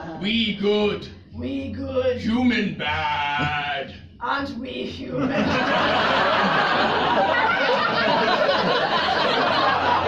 Tags: rap